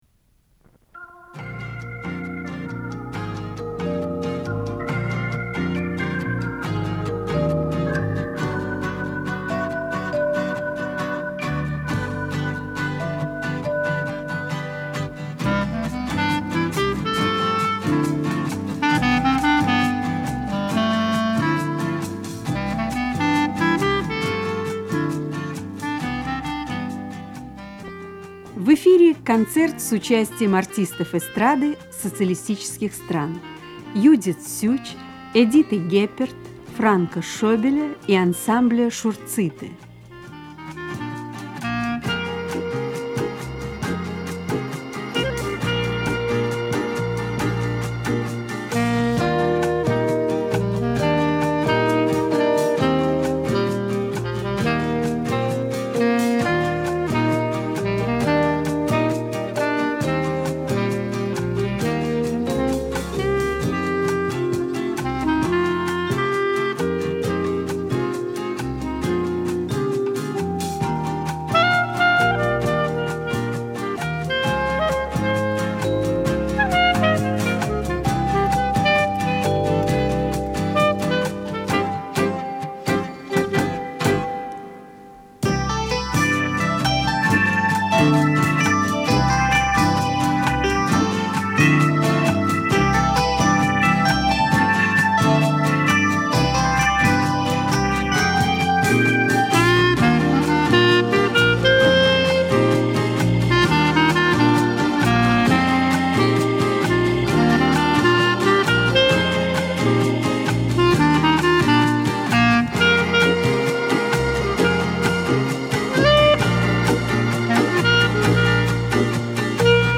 Эстрадный концерт с участием артистов социалистических стран.